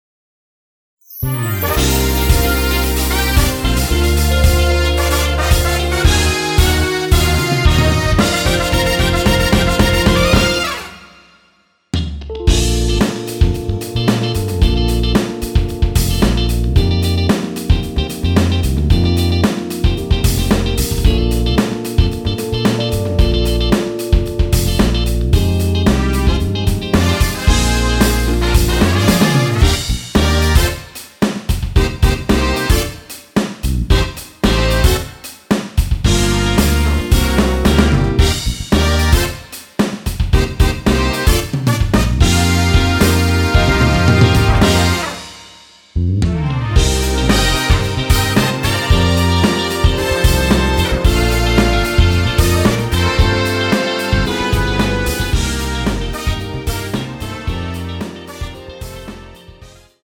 원키에서(+2)올린 MR입니다.
Bb
앞부분30초, 뒷부분30초씩 편집해서 올려 드리고 있습니다.
중간에 음이 끈어지고 다시 나오는 이유는